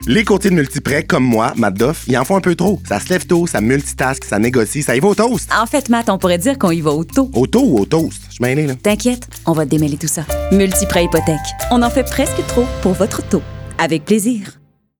Démo de voix
30-45 ans ⸱ Narration ⸱ Publicité
30-45 ans ⸱ Advertising